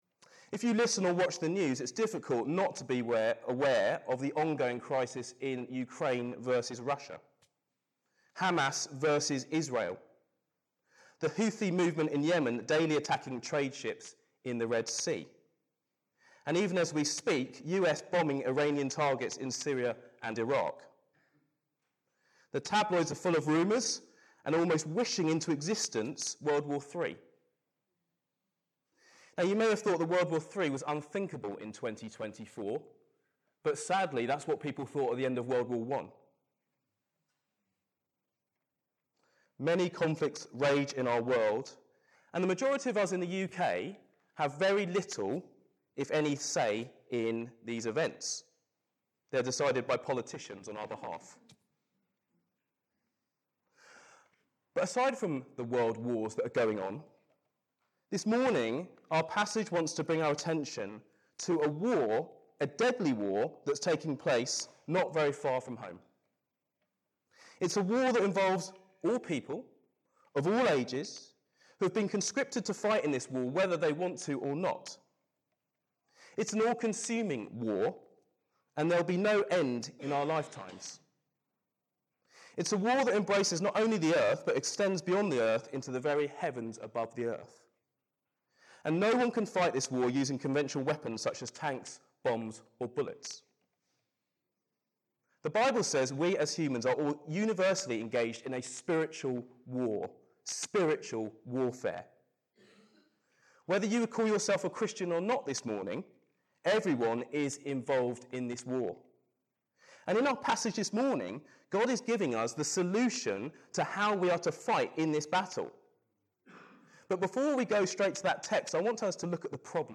This sermon was preached on Sunday 4th February 2024 at CBC Priory Street.